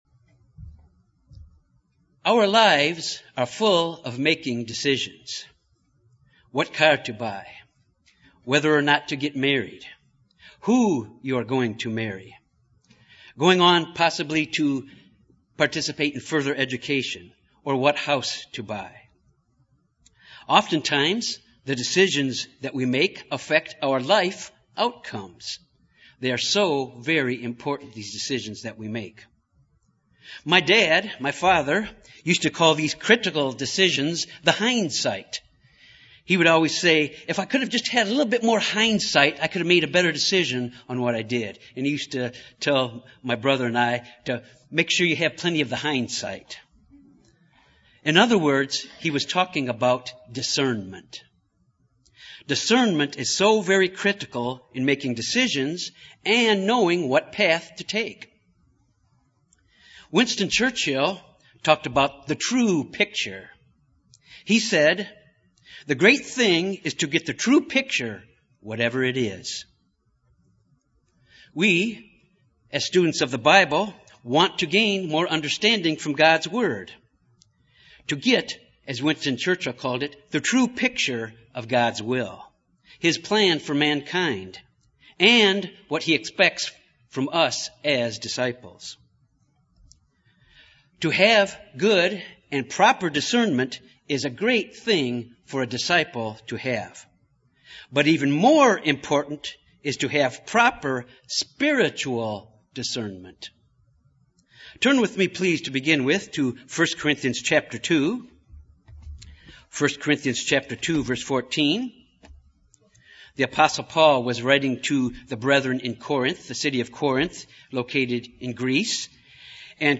Sermons
Given in Little Rock, AR Jonesboro, AR Memphis, TN